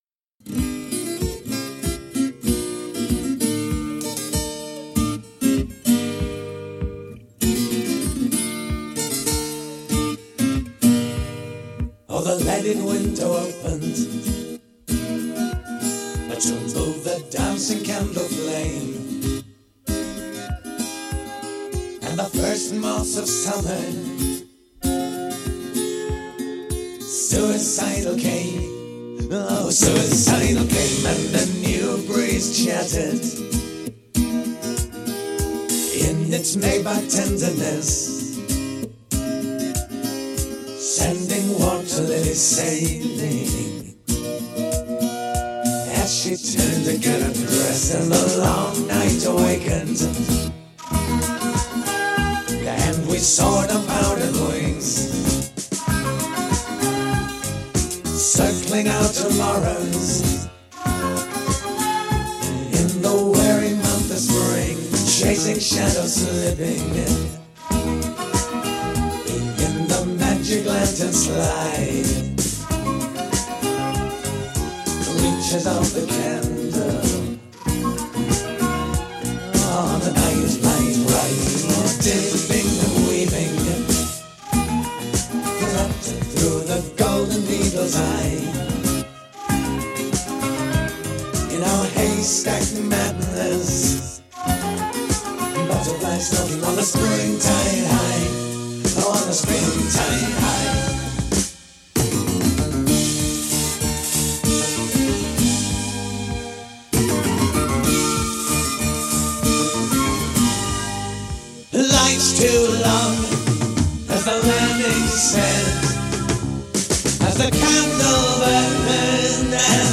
folk leanings
The flute and melody give me butterflies in my stomach.